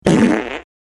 Издавание человеком неприличных, но естественных звуков портящих воздух.
27. Звук пука волосатого водителя грузовика
puk-volosatogo-vod.mp3